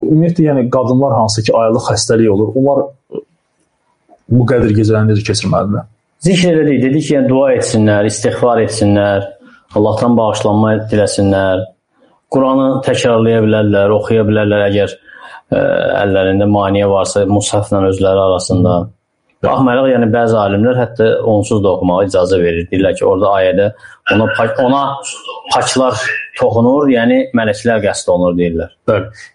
Namaz (SUAL-CAVAB)